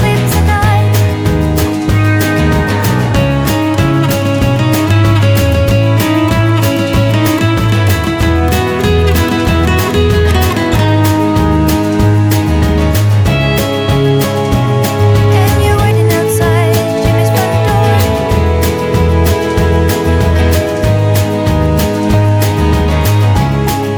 No Electric Guitars Pop (2000s) 3:02 Buy £1.50